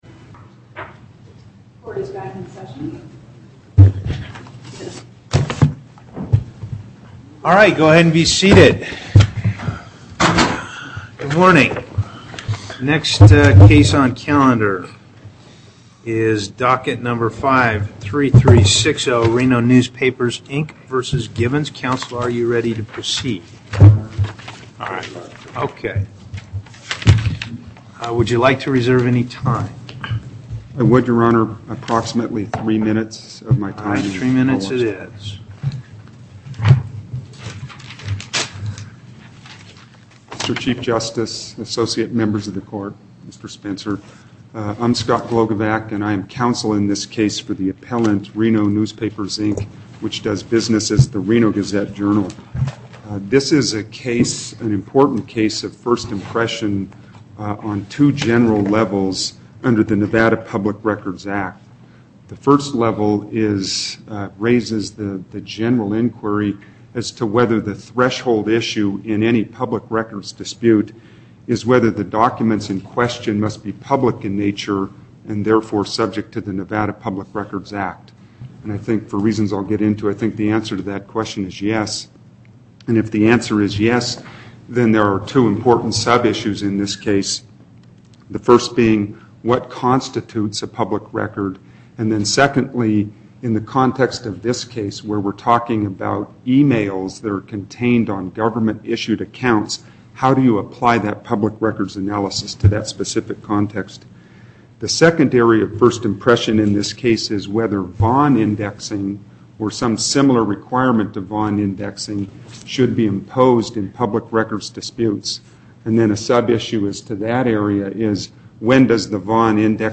Location: Carson City Before the En Banc Court: Chief Justice Parraguirre, Presiding